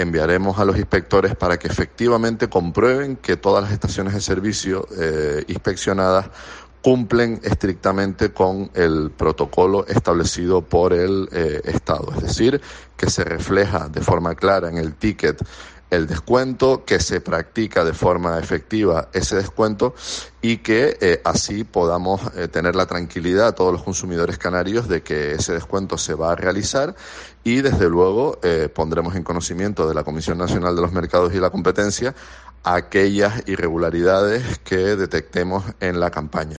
David Mille, director general de Comercio y Consumo, explica cómo será la inspección a las gasolineras